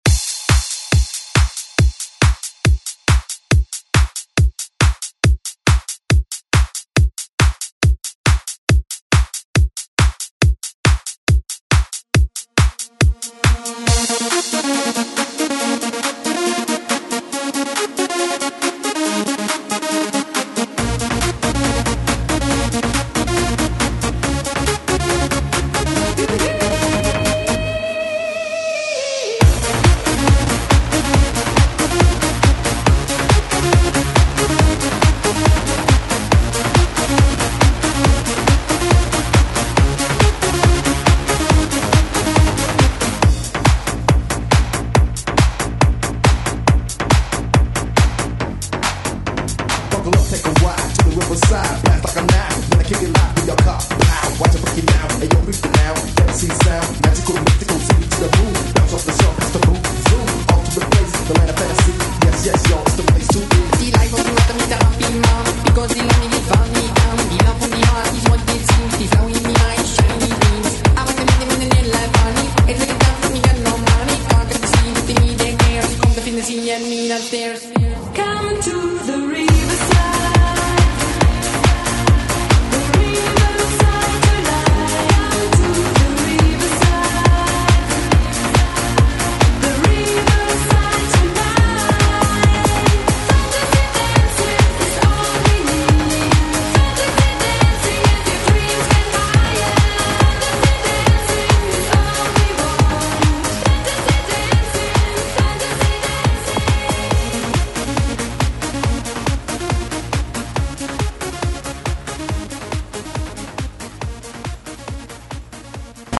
Latin Pop Flamenco Rumba Music Extended ReDrum Clean 104 bpm
Genres: LATIN , RE-DRUM
Clean BPM: 104 Time